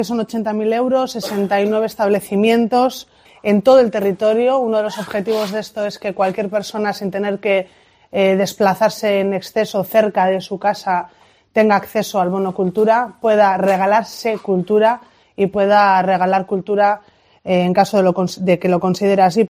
Goizane Álvarez, diputada de Cultura, Cooperación, Juventud y Deportes de Gipuzkoa